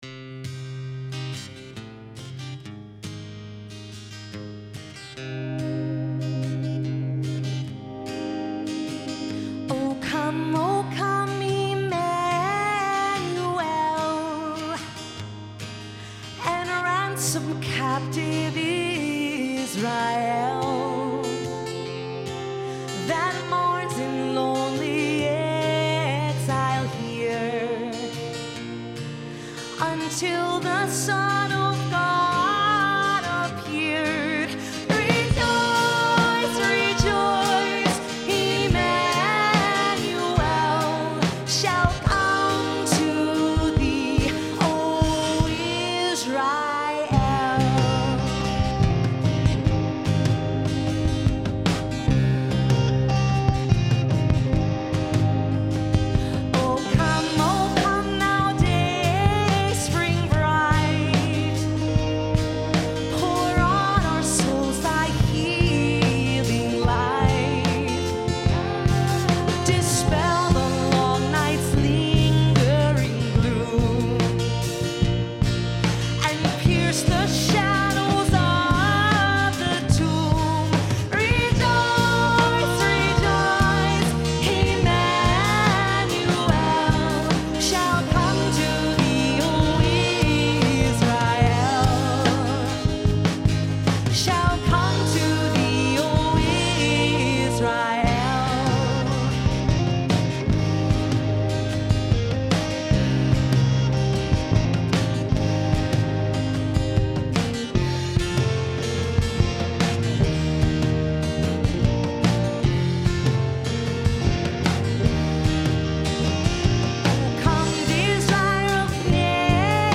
Wayfaring Stranger
Performed live at Terra Nova - Troy on 12/6/09.